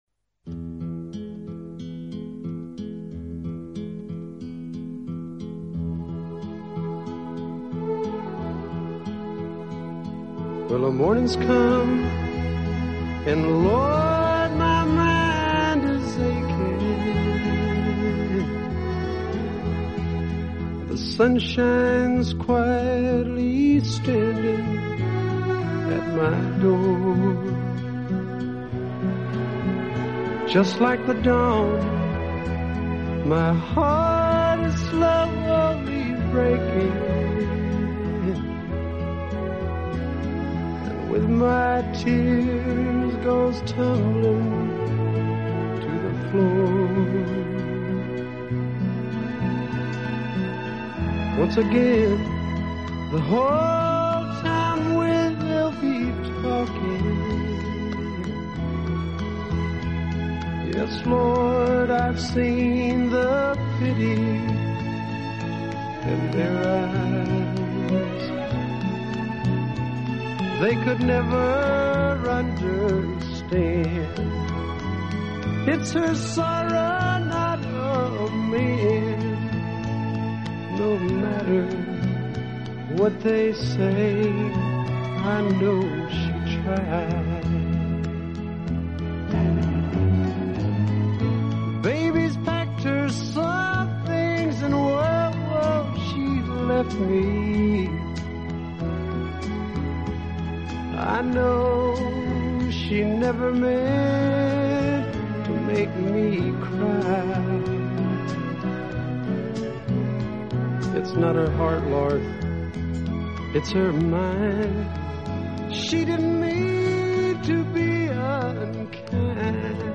Recording type: Studio
Recording mode: Stereo
set of country-pop smashes.